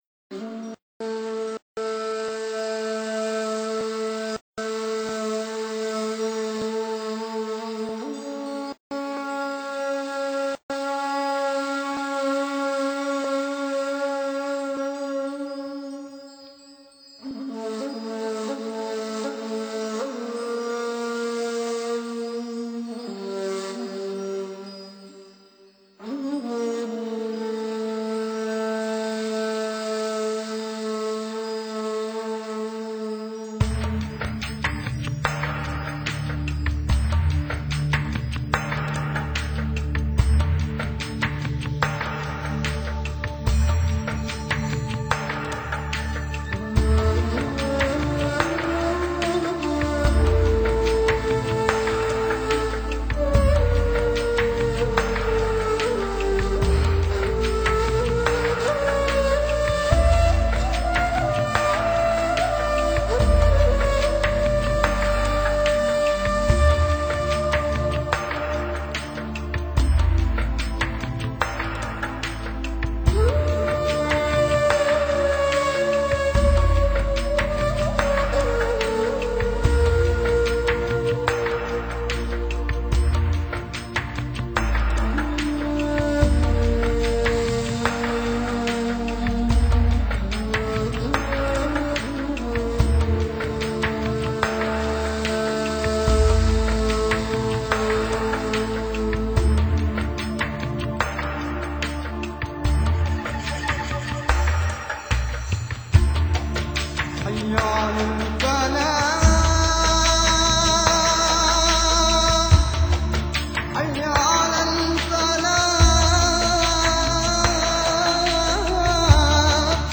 专辑语言：纯音乐
金属片的振响沉淀在尺八低沉而圆润的节奏中，电子音乐的旋律，哄托起尺入的低郁。